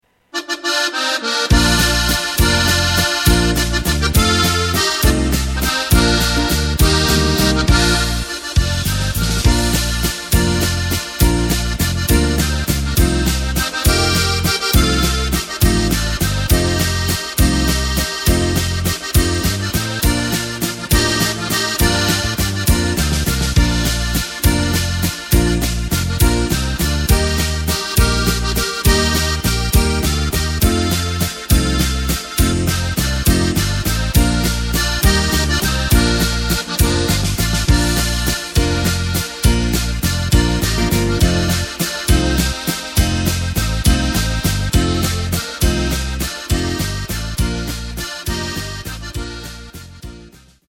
Takt:          3/4
Tempo:         204.00
Tonart:            Bb
Walzer aus dem Jahr 2016!
Playback mp3 mit Drums und Lyrics